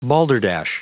Prononciation du mot balderdash en anglais (fichier audio)
Prononciation du mot : balderdash